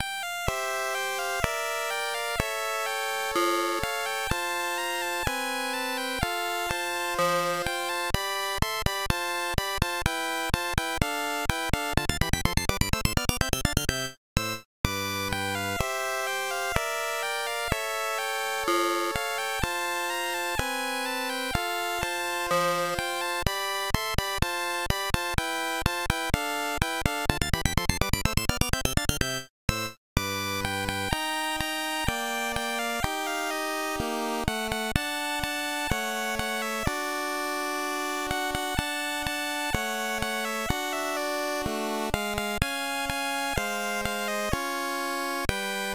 Commodore SID chip chiptune
As played by the sidplay2 emulator player.
The track is loopable